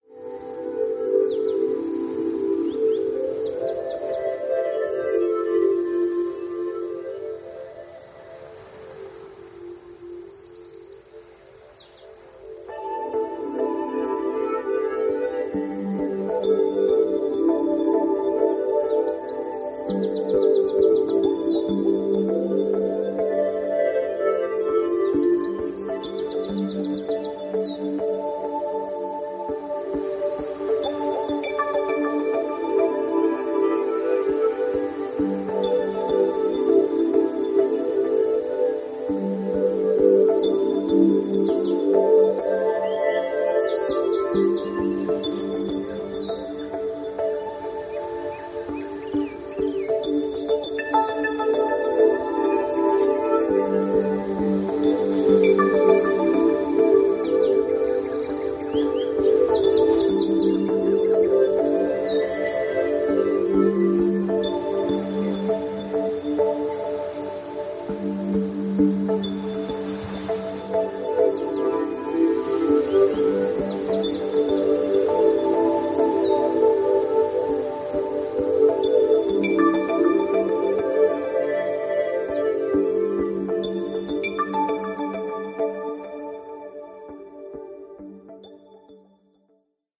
ハウス/ダウンビートを軸にバレアリックな雰囲気を纏った、ノスタルジックで素敵な1枚。